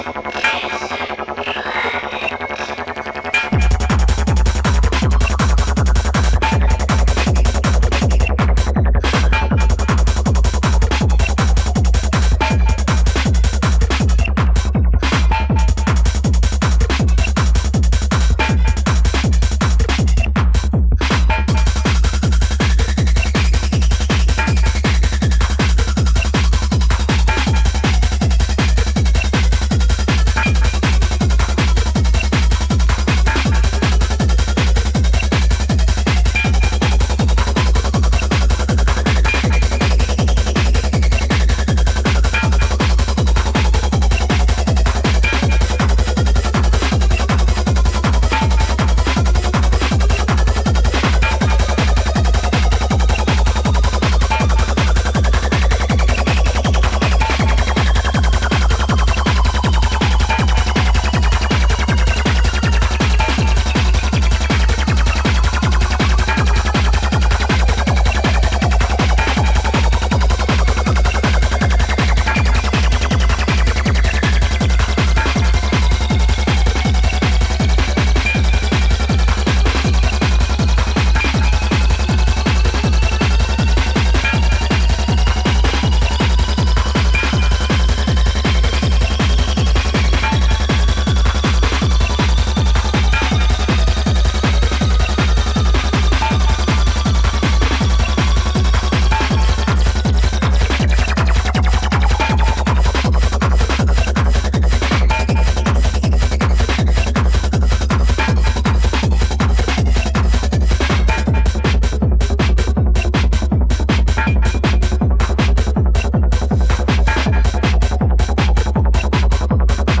With a groovy acid line and shimmering bell textures